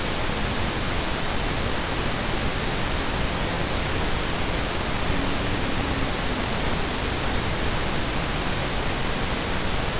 Below I have included five audio samples that contain the phrase “Can you hear the EVP?” They start out simple, with a decent signal strength but they get progressively harder to make out as the signal becomes weaker and embedded deeper into the noise floor.